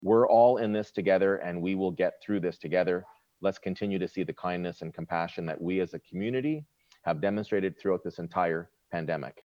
Speaking at city council’s virtual meeting on Monday, just hours after the province announced the loosening of some lockdown rules, Mayor Mitch Panciuk outlined some of the changes.